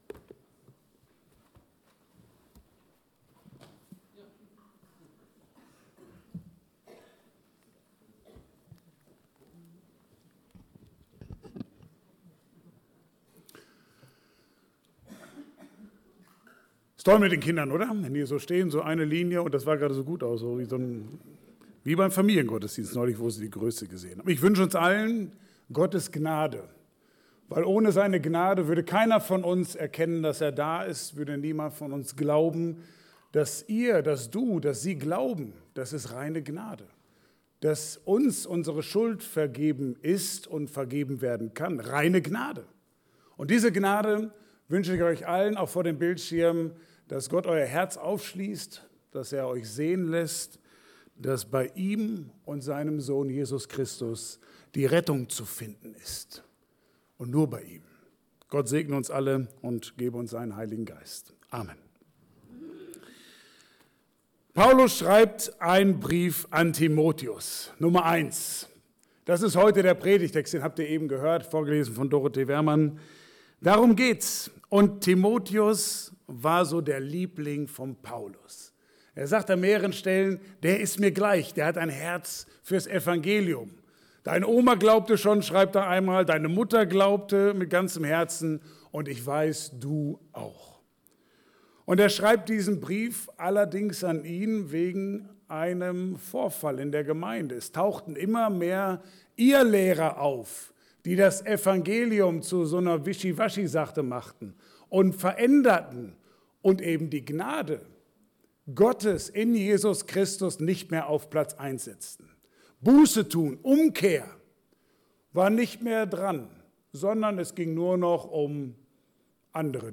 Passage: 1.Timotheus 1, 12-17 Dienstart: Gottesdienst « Gott bringt Seine Einladung an Dich immer wieder in Erinnerung Vorsicht Grube!